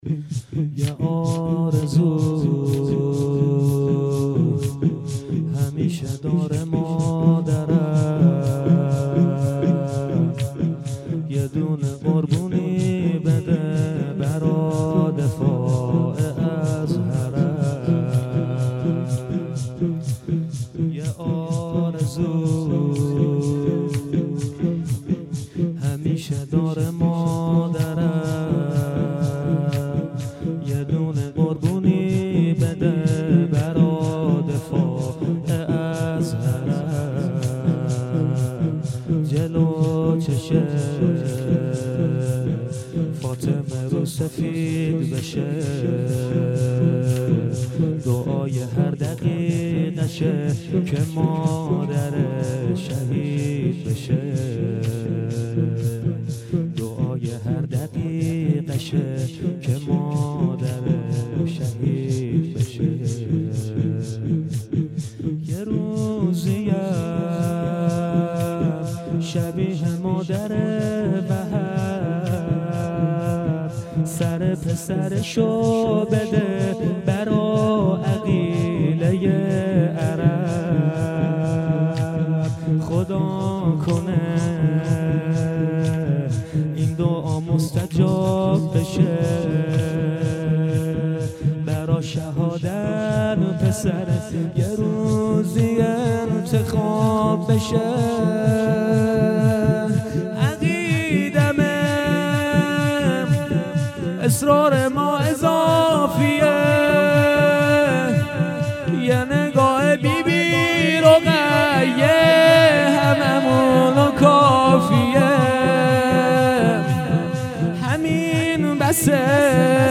خیمه گاه - هیئت پیروان علمدار شهرری - شور پایانی
هیئت پیروان علمدار شهرری